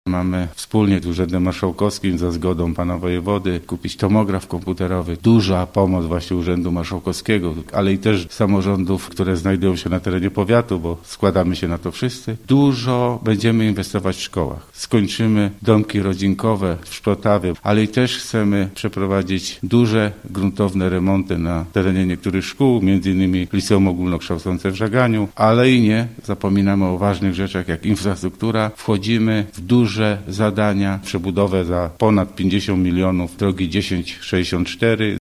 – Jednym z priorytetów jest inwestycja w służbę zdrowia – mówi Henryk Janowicz, starosta żagański: